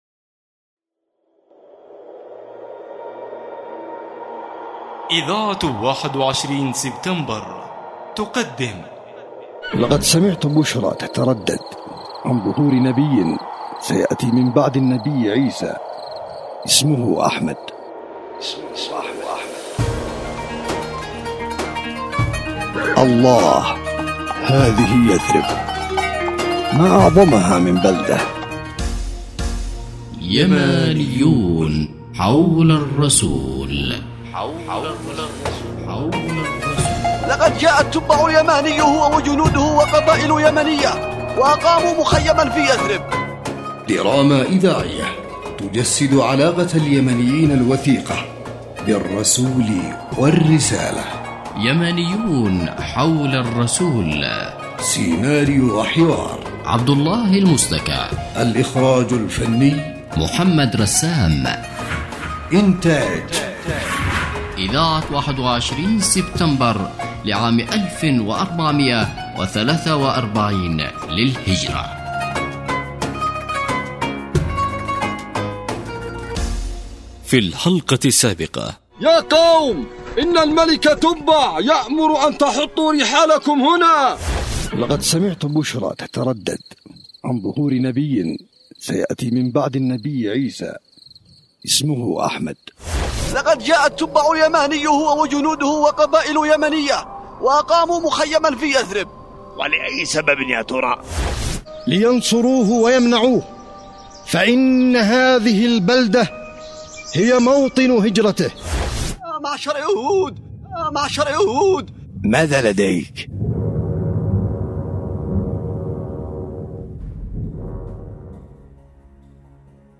دراما يمانيون حول الرسول